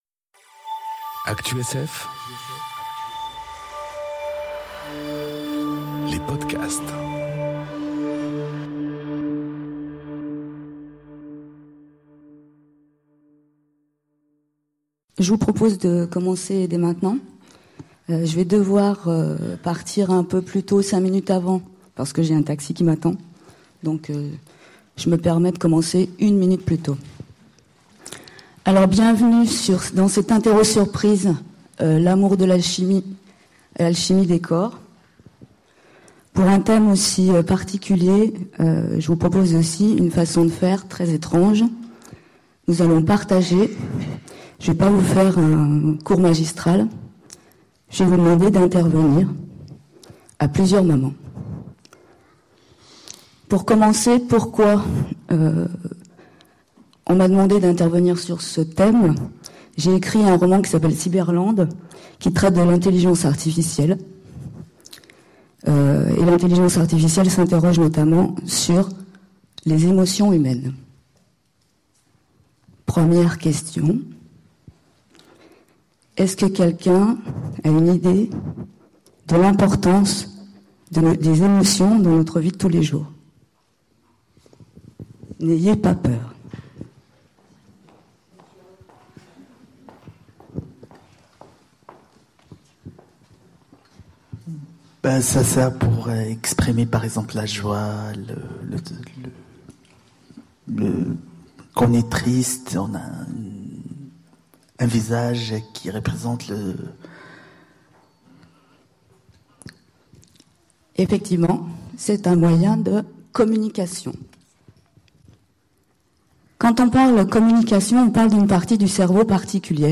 Conférence L'Amour, de la chimie à l'alchimie des corps aux Utopiales 2018